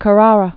(kə-rärə, kär-rärä)